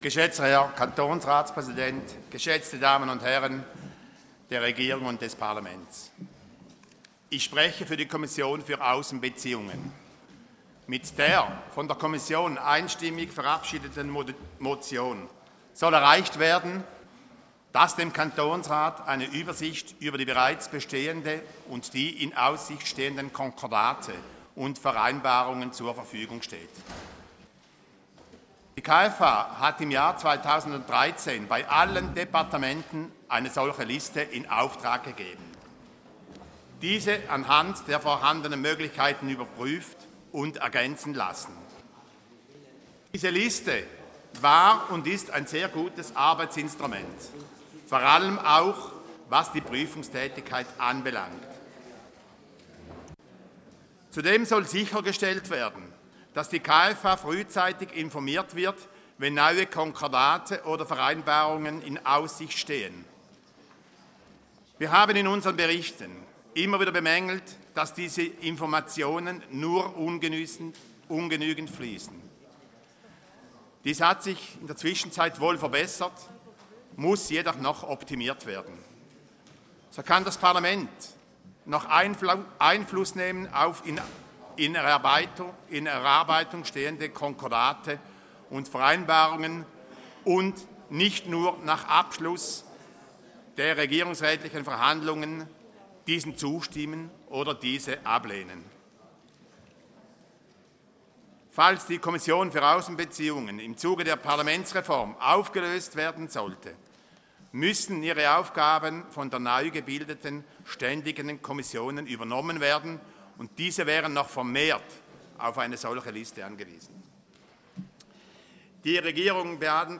2.6.2015Wortmeldung
Session des Kantonsrates vom 1. bis 3. Juni 2015